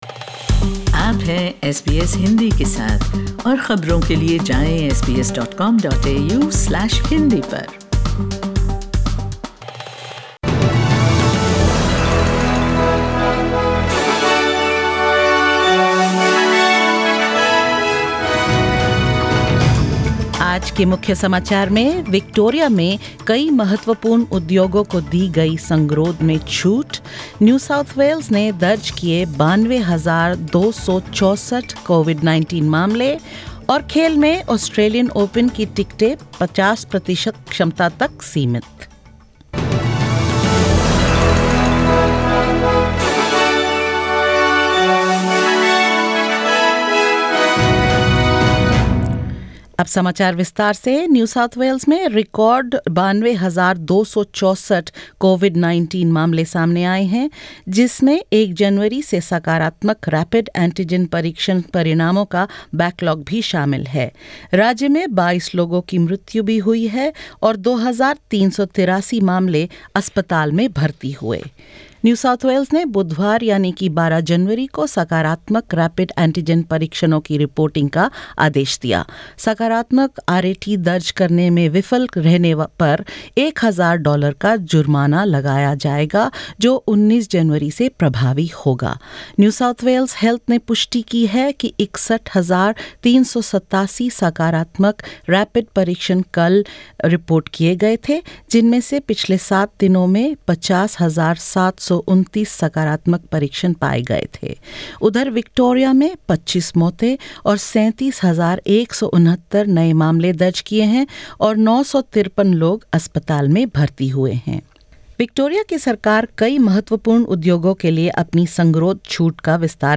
In this latest SBS Hindi news bulletin: Victoria announces more quarantine exemptions for a range of critical industries; New South Wales records 92,264 COVID-19 cases including a backlog of positive rapid antigen tests results; Tickets to the Australian Open paused at 50 per cent capacity and more.